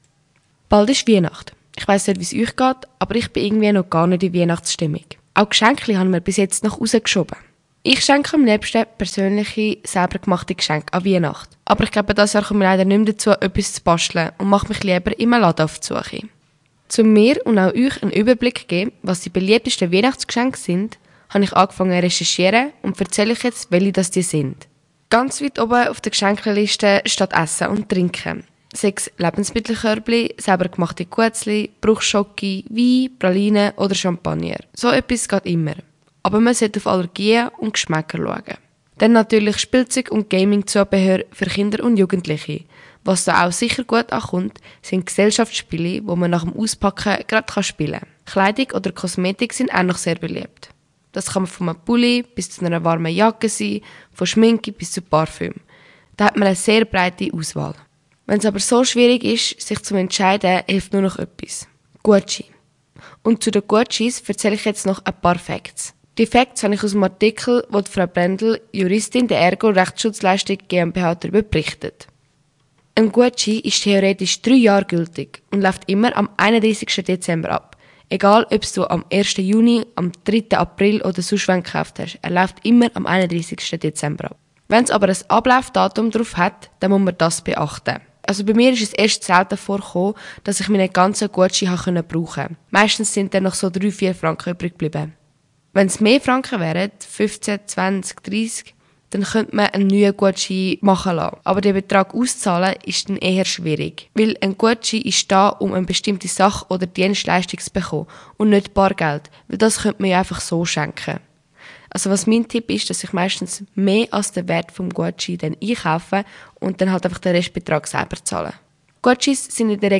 In meinem heutigen Radiobeitrag spreche ich darüber, was dieses Jahr unter den Weihnachtsbäumen liegt. Gutscheine stehen dabei ganz oben auf der Liste der beliebtesten Geschenke – praktisch, flexibel und für viele die Rettung in der Last-Minute-Krise. Mein Beitrag beleuchtet ausserdem die wichtigsten Punkte, die man beim Verschenken und Einlösen von Gutscheinen im Blick behalten sollte.